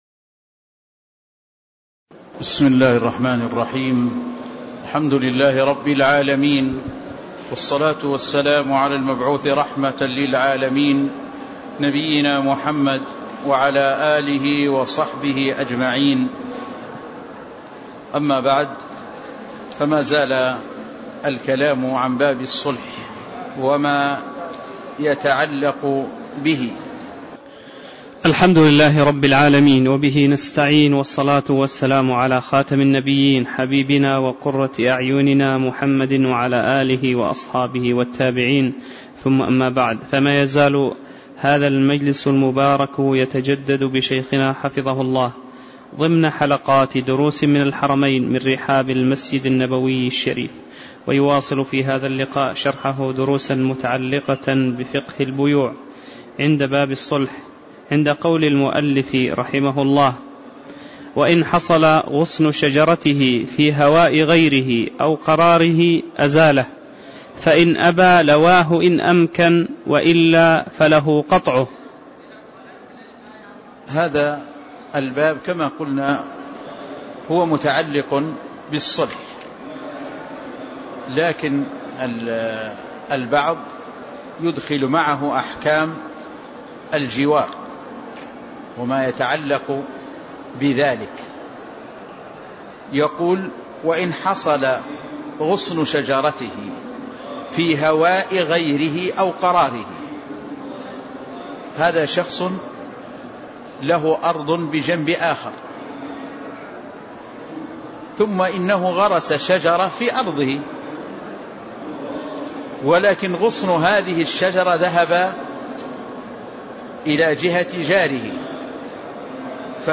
تاريخ النشر ٢١ محرم ١٤٣٧ هـ المكان: المسجد النبوي الشيخ